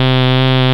WASPY.wav